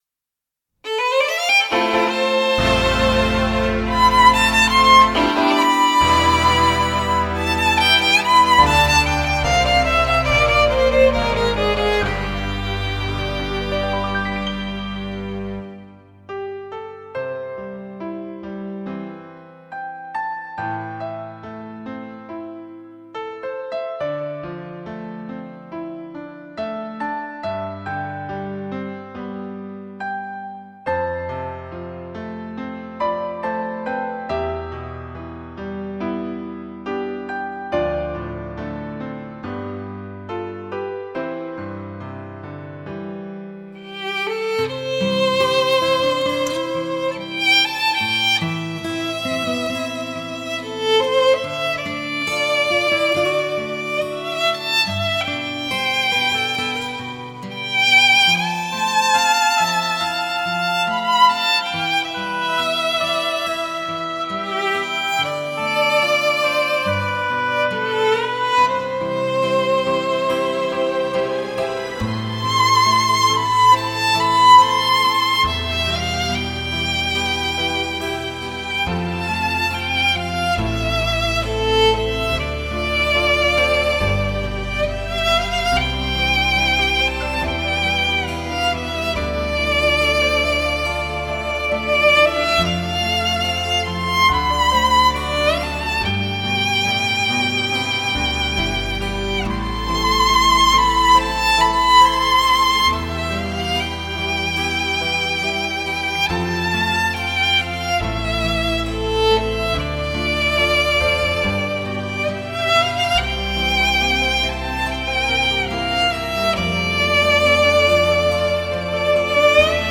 音色精致迷人自然纯朴优美，发烧友超值满意之作，
浓浓松香味精彩连弓控制瑰丽琴音不二之选。